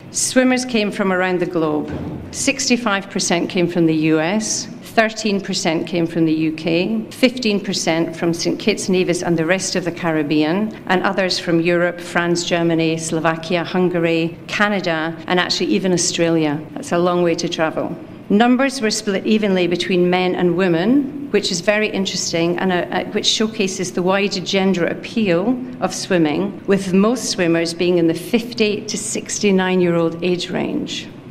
A Media launch was held at Nevis’ Malcolm Guishard Recreational Park at Pinneys for the hosting of the 2026 Nevis to St. Kitts Cross Channel Swim on Friday, January 9th, 2026.